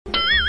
SFXduang音效下载
SFX音效